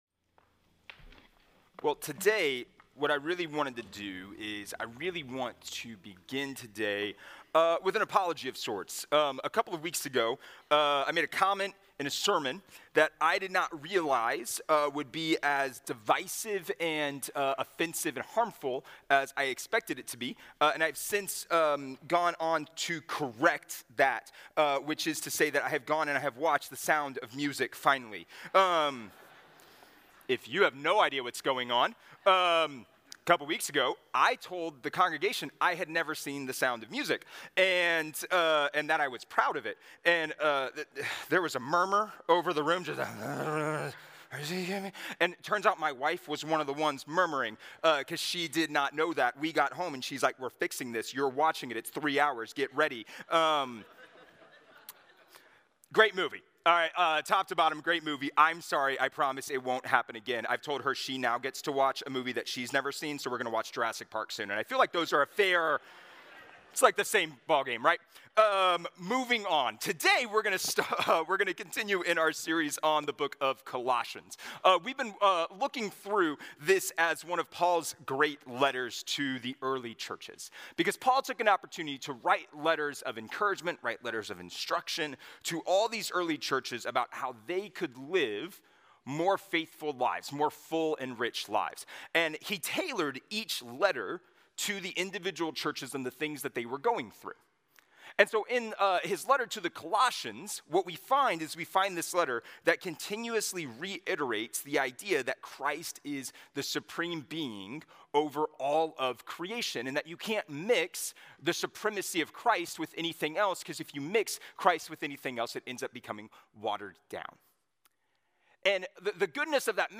A message from the series "Dear Church."